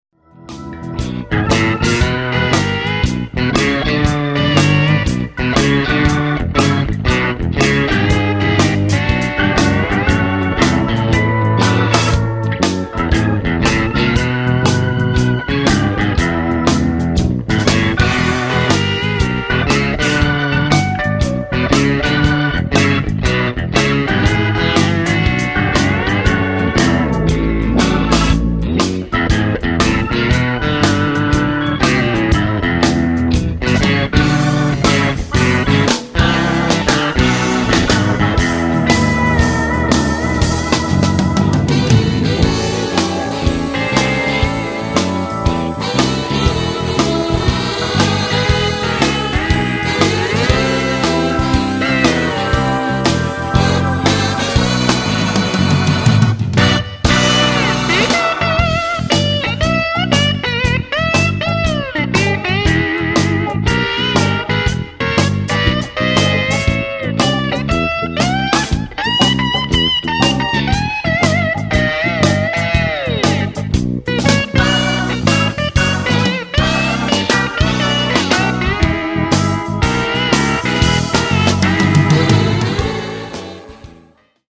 slide guitar